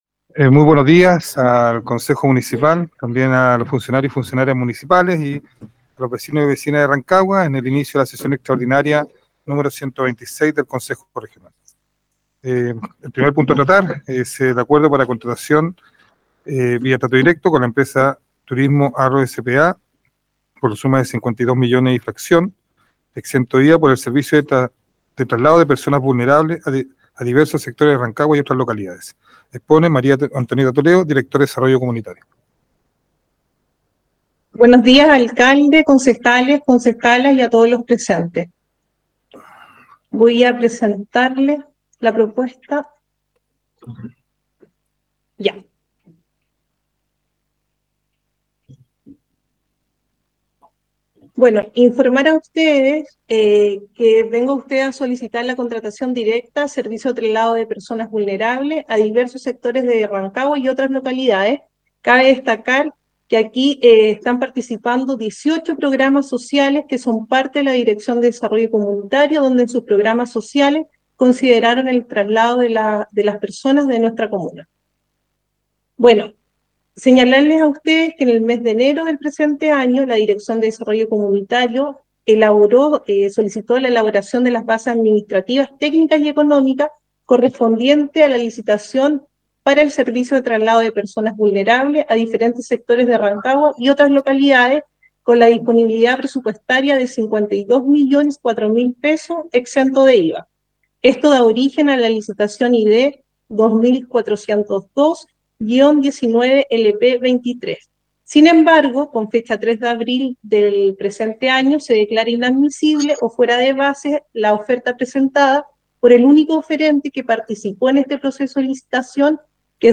Sesión del Concejo Municipal Extraordinario número 126, efectuado el jueves 27 de julio de 2023.